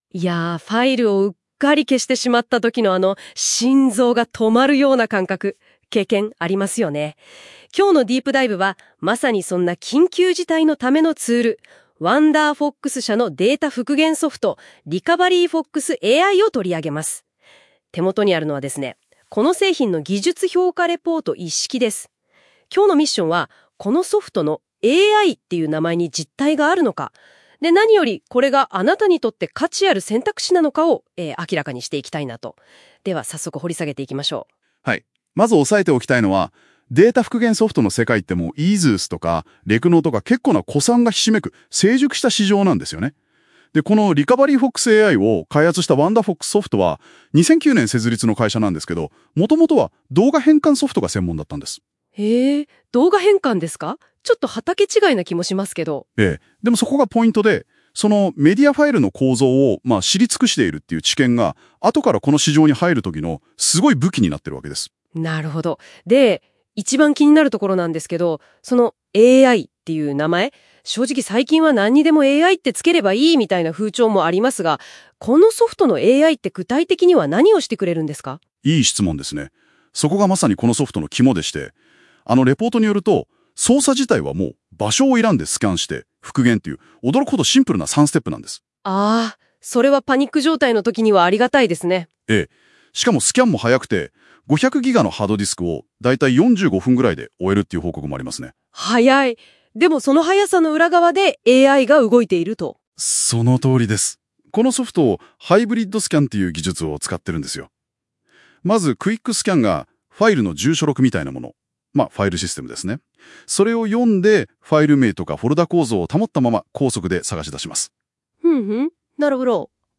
【音声解説】ついうっかり削除してしまった時に『RecoveryFox AI』